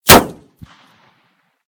/ gamedata / sounds / material / bullet / collide / metall02gr.ogg 20 KiB (Stored with Git LFS) Raw History
metall02gr.ogg